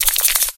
emz_reload_01.ogg